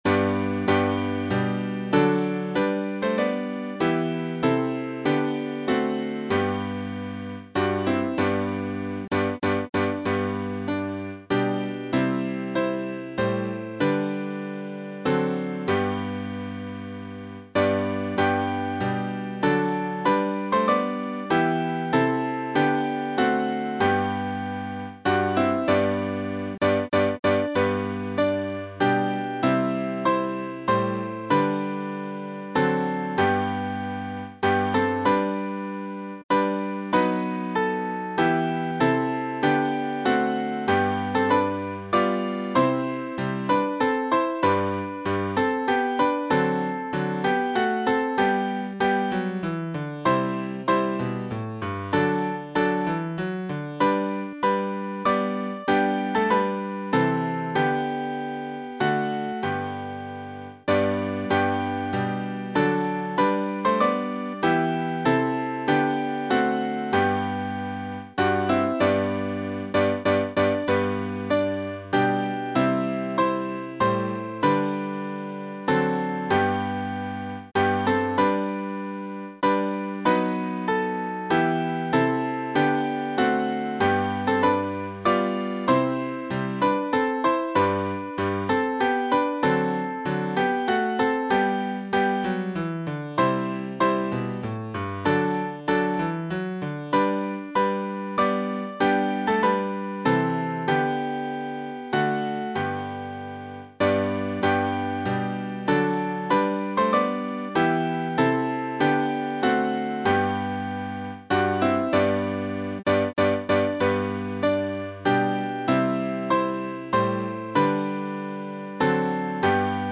Midi File Transcriptions